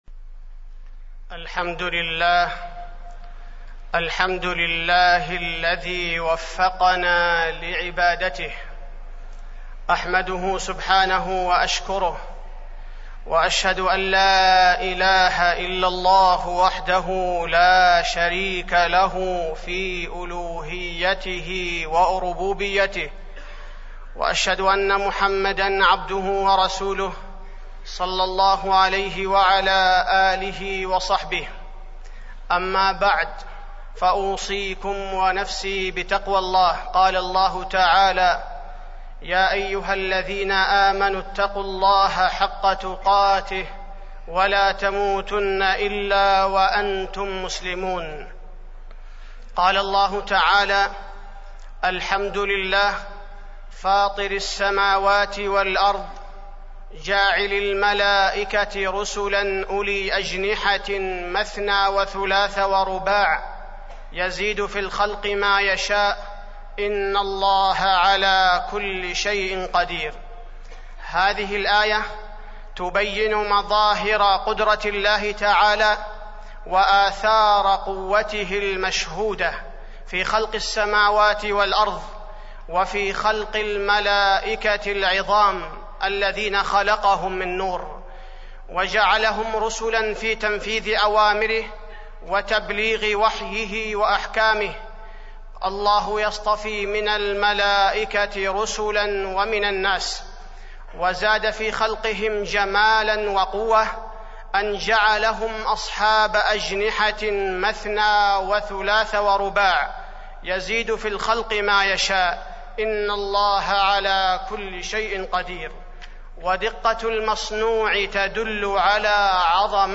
تاريخ النشر ٢٣ جمادى الآخرة ١٤٢٦ هـ المكان: المسجد النبوي الشيخ: فضيلة الشيخ عبدالباري الثبيتي فضيلة الشيخ عبدالباري الثبيتي الإيمان بالملائكة